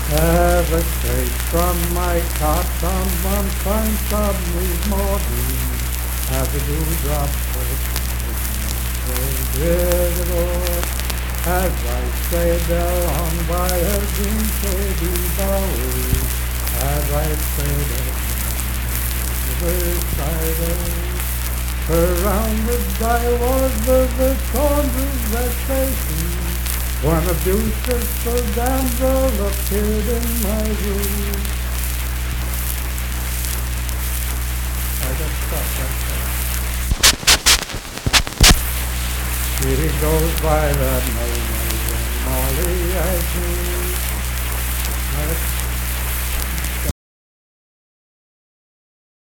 Unaccompanied vocal music performance
Richwood, Nicholas County, WV.
Miscellaneous--Musical
Voice (sung)